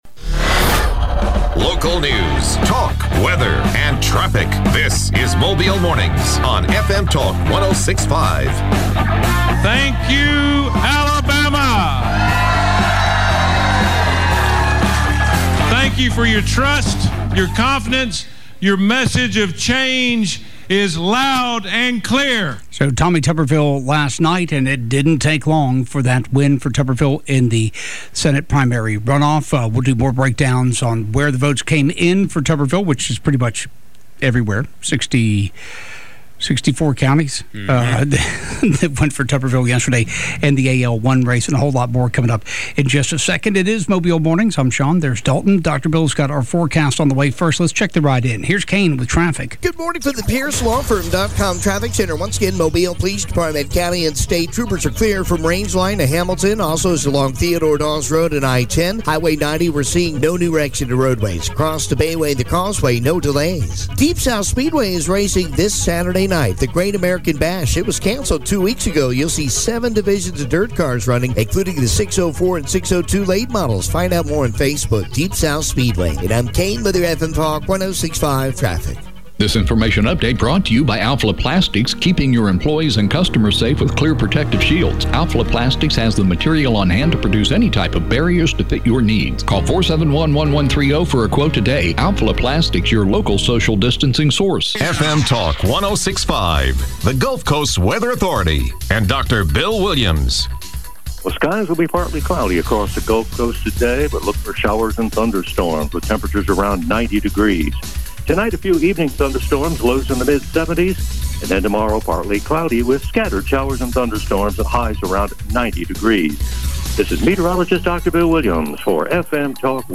Other subjects include Covid-19 stats, runoff election. Guests include Jerry Carl